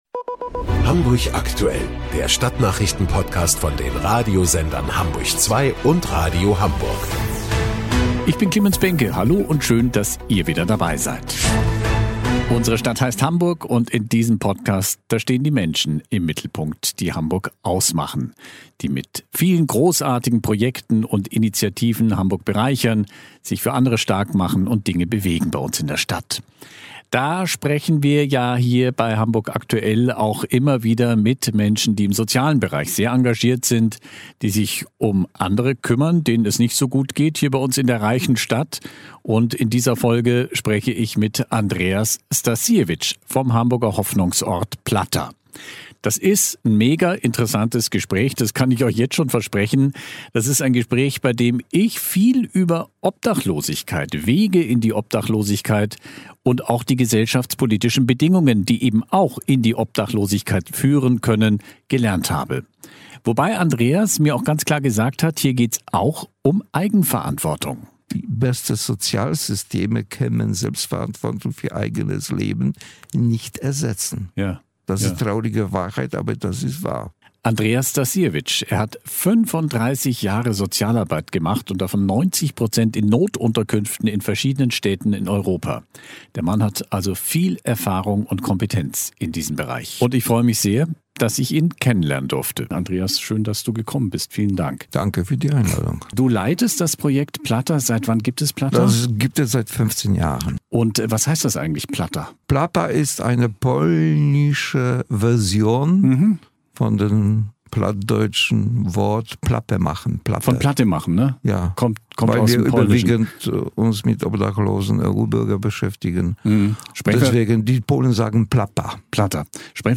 Genres: Daily News , News